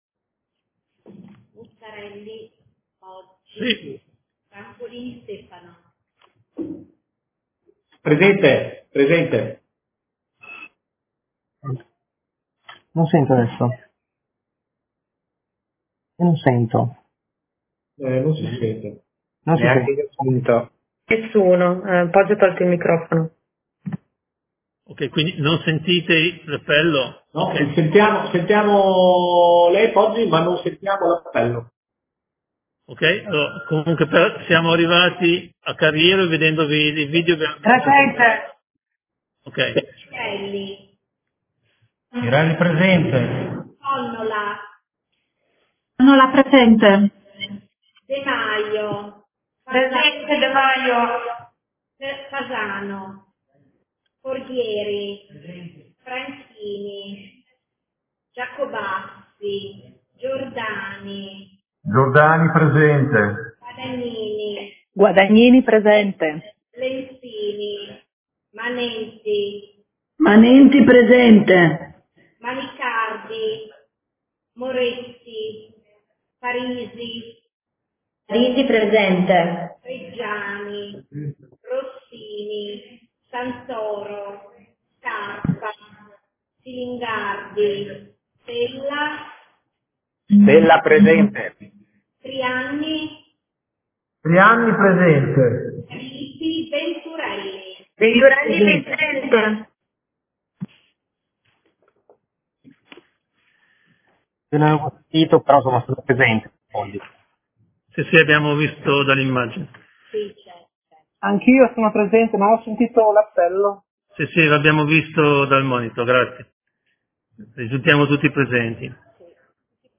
APPELLO
Vice Segretaria Generale